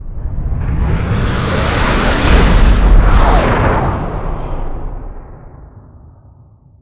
KlingonEnterWarp.wav